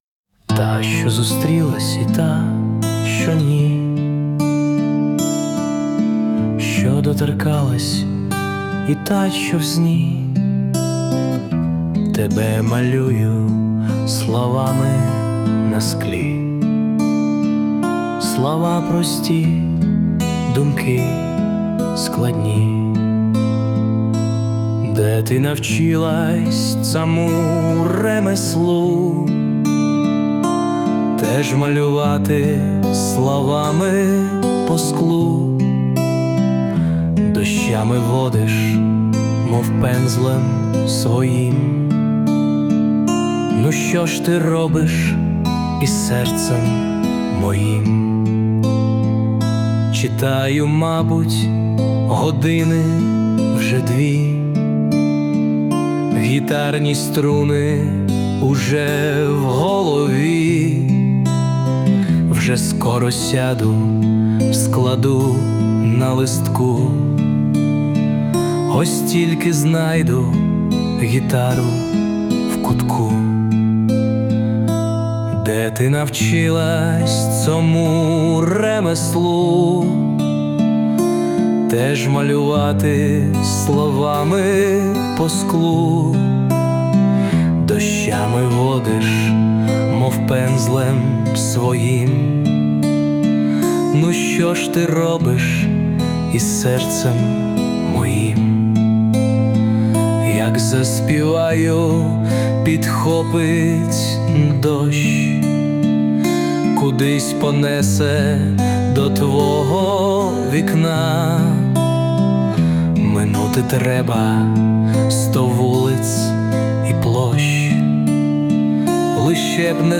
Запис_без_перепадів.mp3
Власне виконання
Перепади звуку усунуто з допомогою SUNO
СТИЛЬОВІ ЖАНРИ: Ліричний